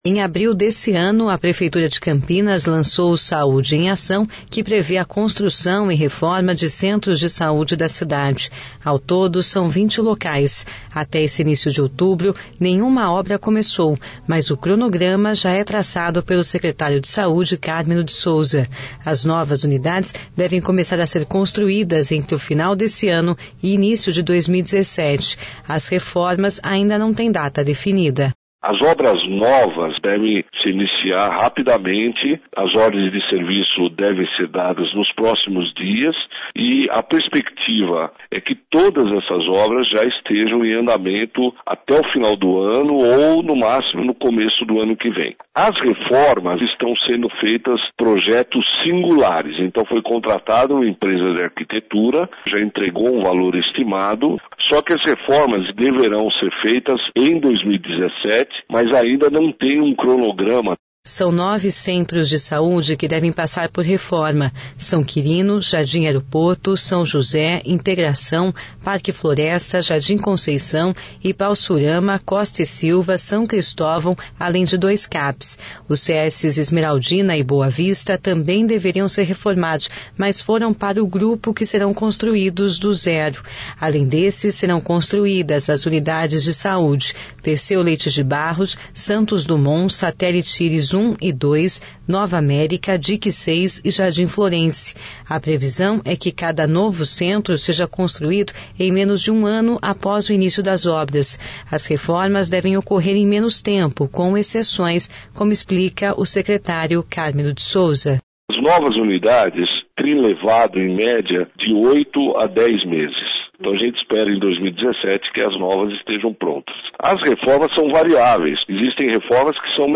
As reformas devem ocorrer em menos tempo, com exceções, com explica o Secretário Cármino de Sousa.